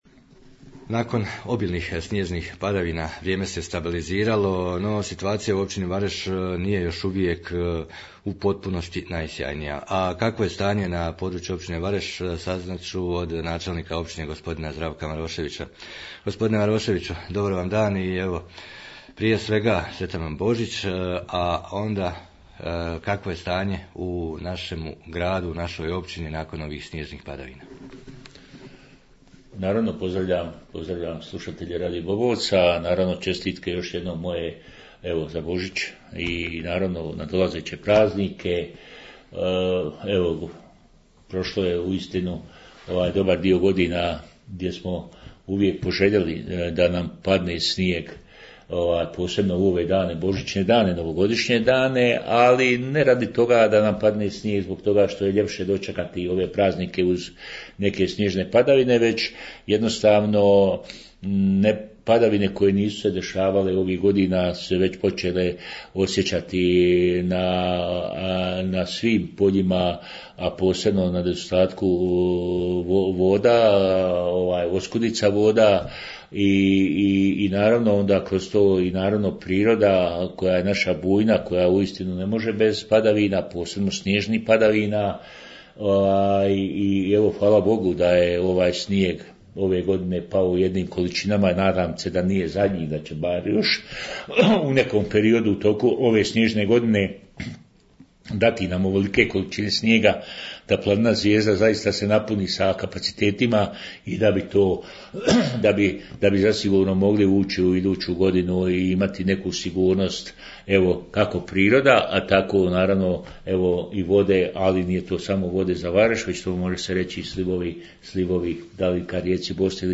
Razgovor s načelnikom Maroševićem o stanju u općini nakon sniježnih padavina